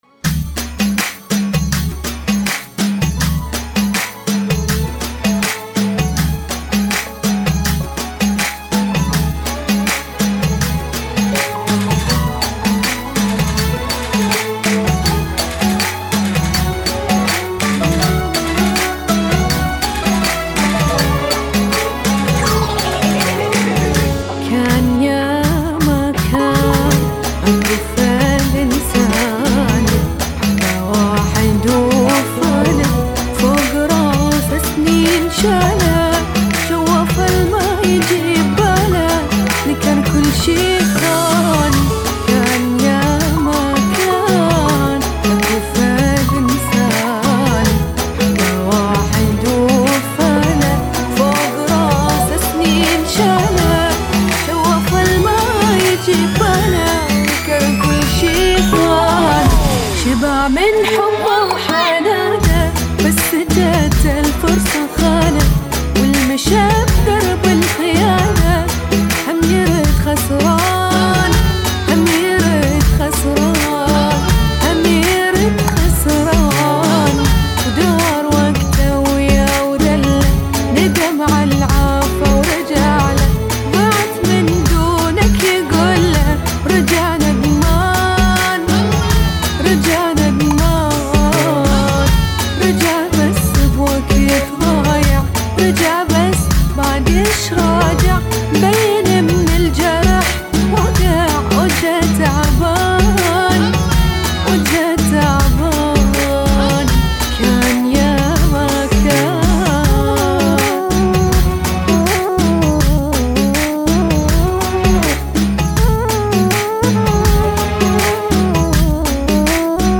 [ 81 bpm ]